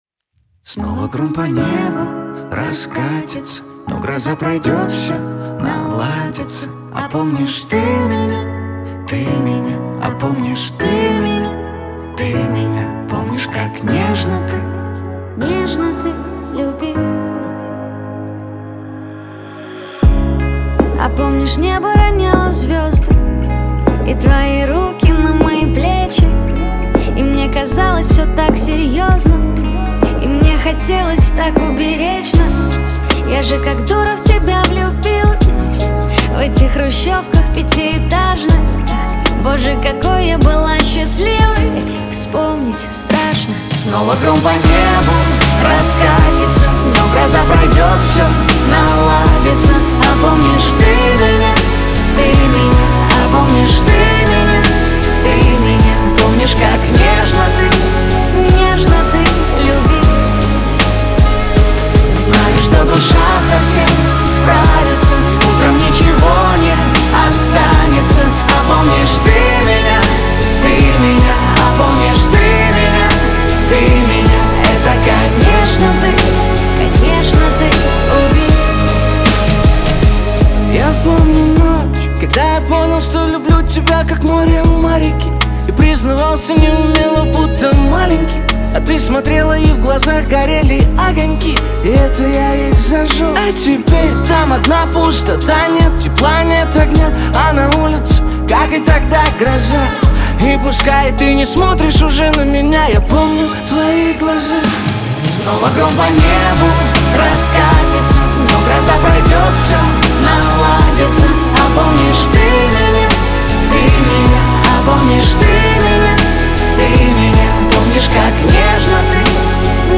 Жанр: ruspop